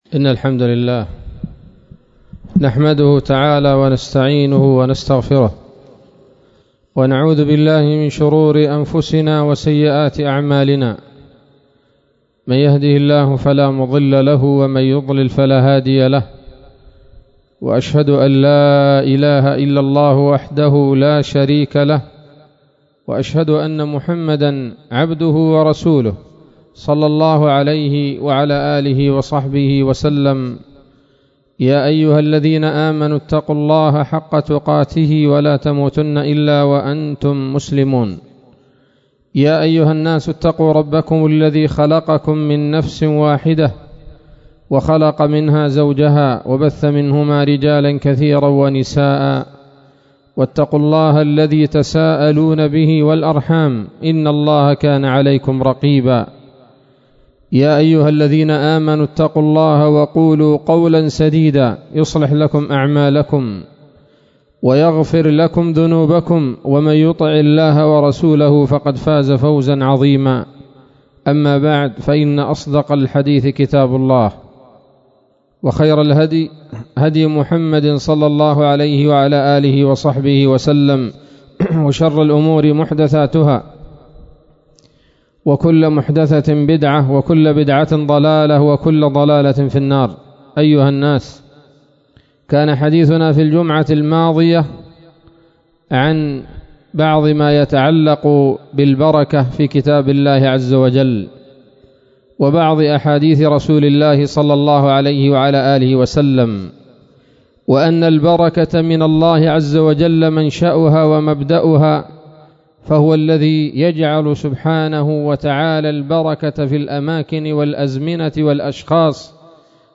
خطبة جمعة بعنوان: (( الدعاء بالبركة )) 2 من ذي القعدة 1440 هـ